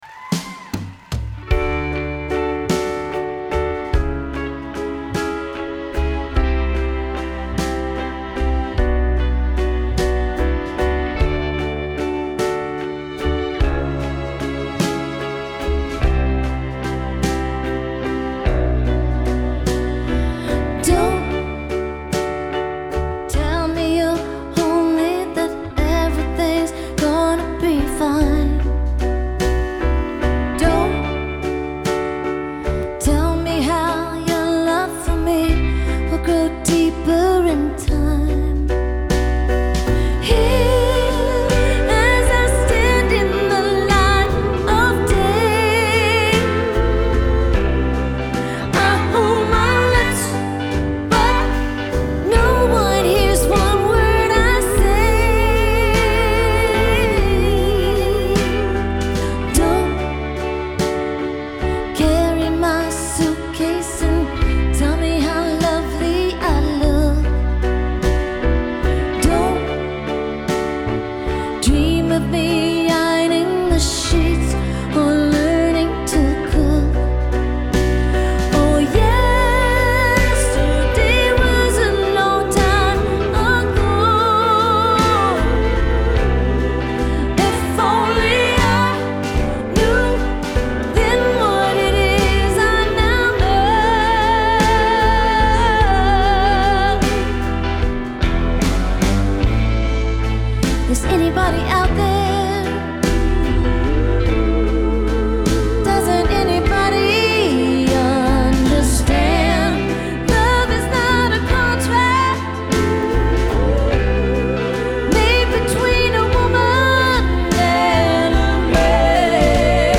Genre : Pop
Live from the Theatre at Ace Hotel